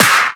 Snare 10.wav